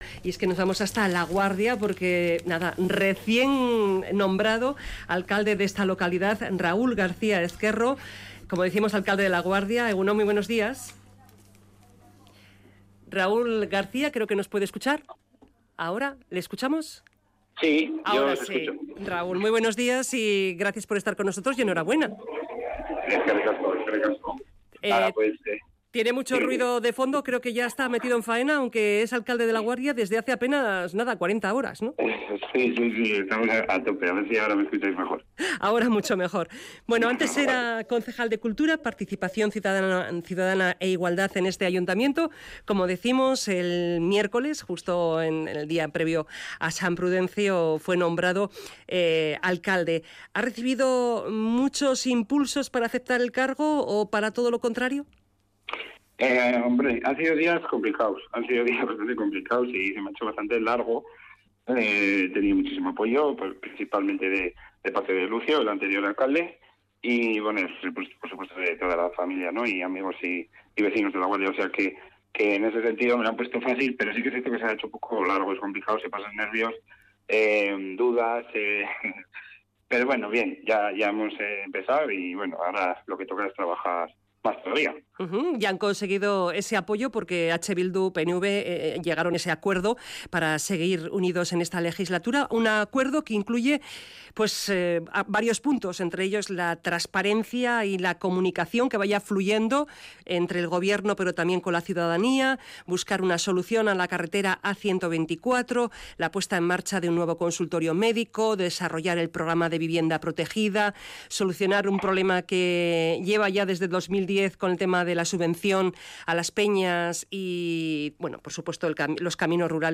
Entrevistado en Radio Vitoria Raúl García Ezquerro, recién nombrado alcalde de Laguardia. El alcalde reconoce que hay mucho trabajo por delante en este año pero que se pueden hacer. Asegura que todavía no ha pensando en la futura campaña electoral.